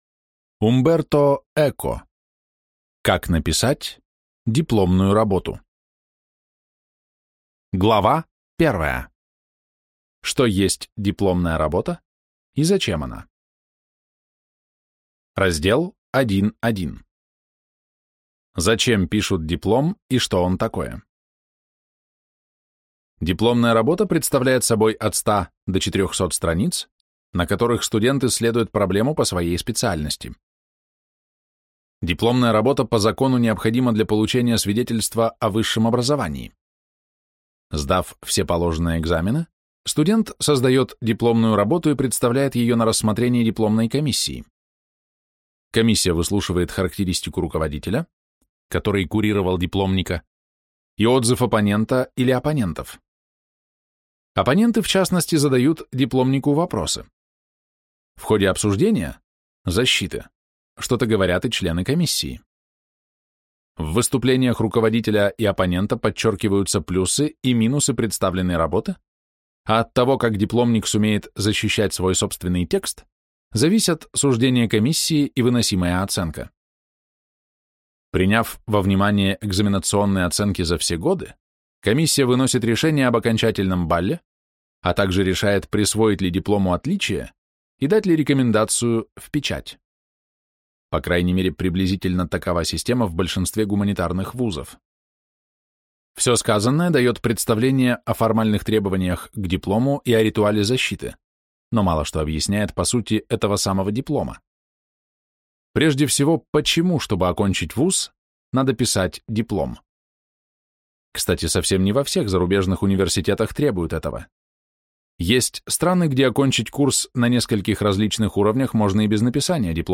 Аудиокнига Как написать дипломную работу. Гуманитарные науки | Библиотека аудиокниг